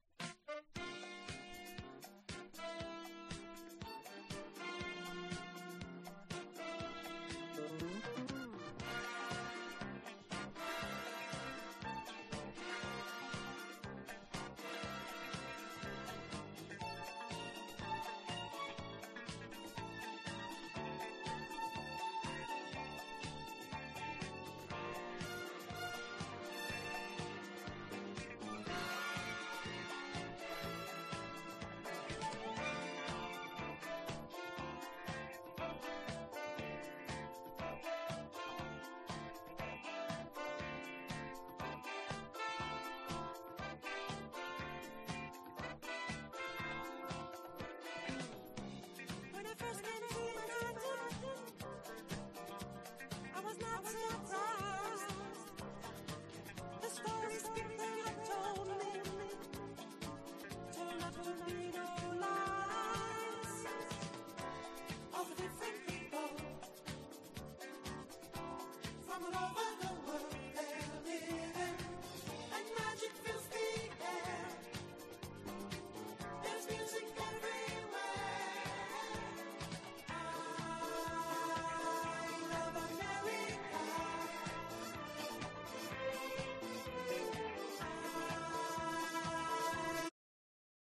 # LOFT / GARAGE